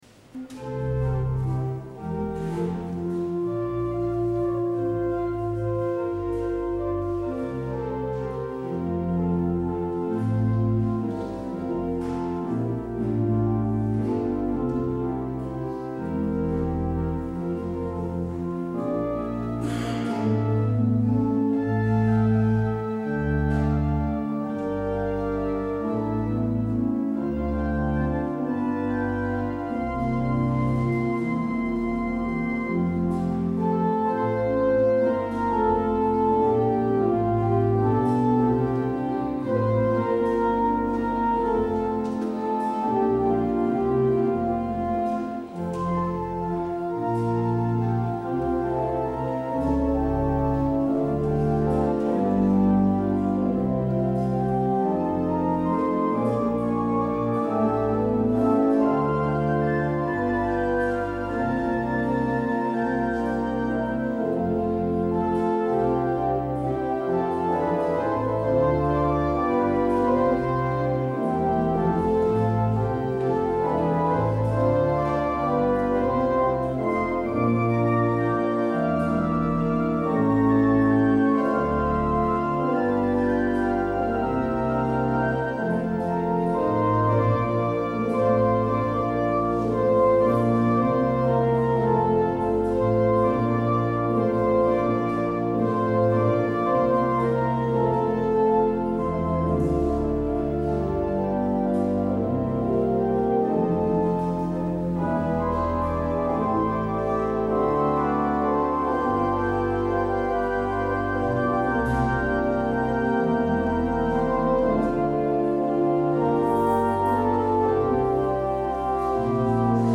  Luister deze kerkdienst hier terug
Het openingslied is Psalm 24: 1 en 5.